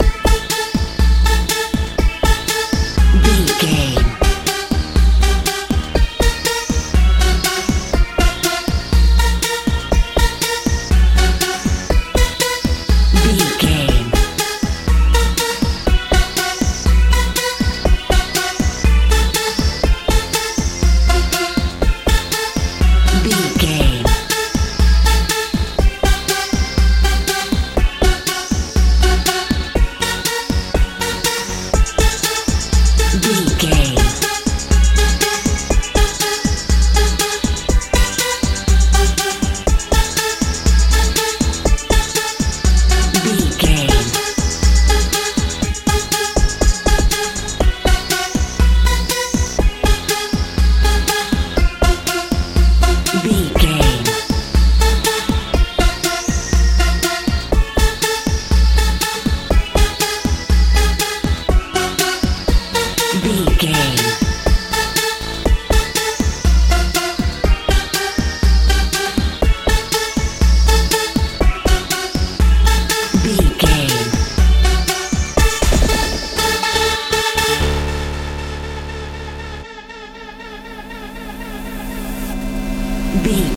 euro dance feel
Ionian/Major
magical
mystical
synthesiser
bass guitar
drums
80s
90s
tension
suspense
strange